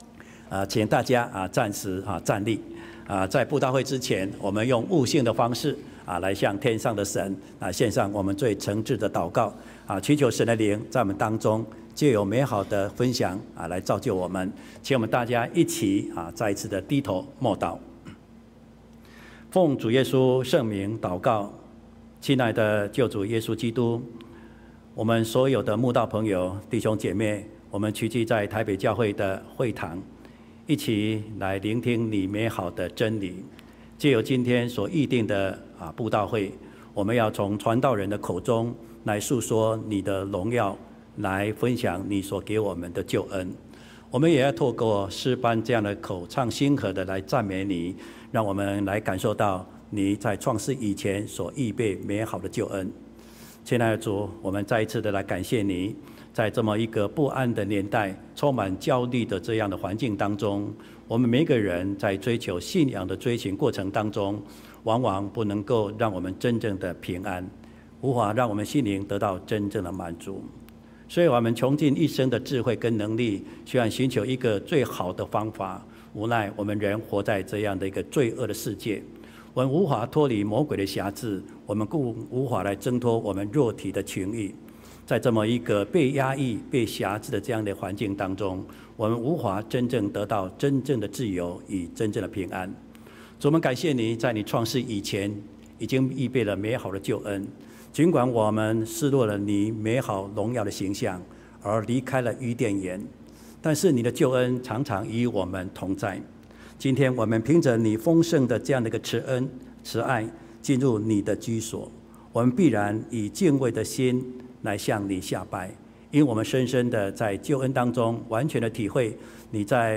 秋季靈恩佈道會：直湧到永生-講道錄音